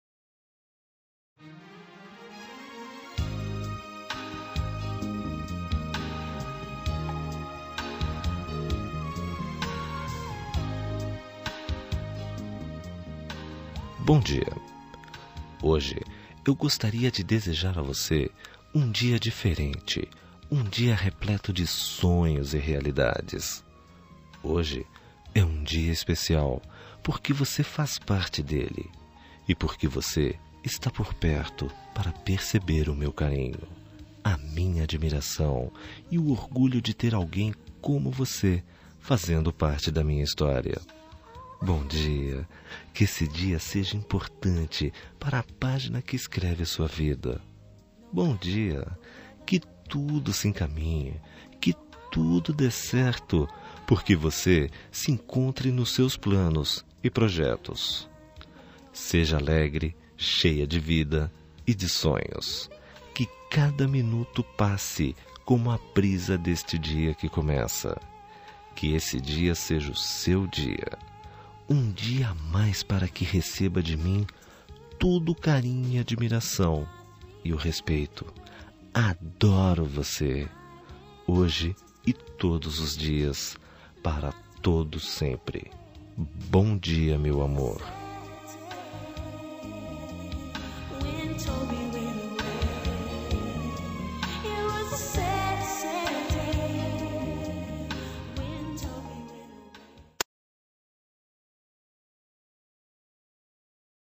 Telemensagem de Bom Dia – Voz Masculina – Cód: 6327 – Geral
6327-dia-neutra-masc.mp3